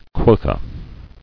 [quo·tha]